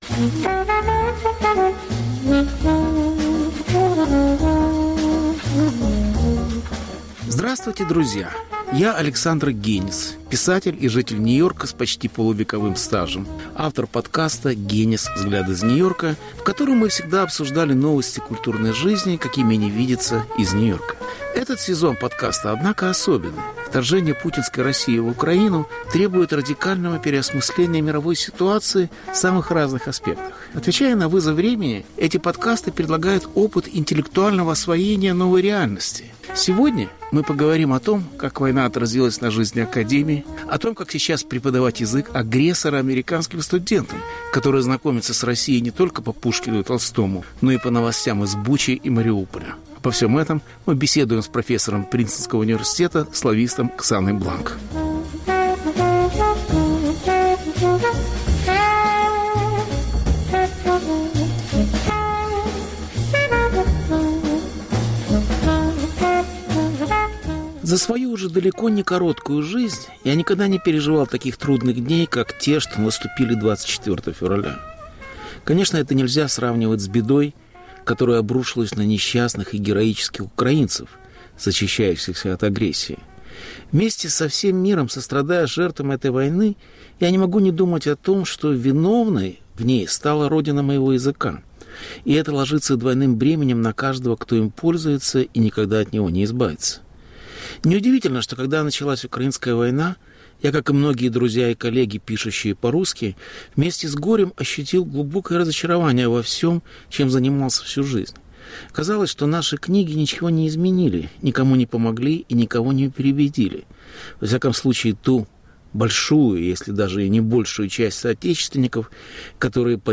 Повтор эфира от 19 июня 2022 года.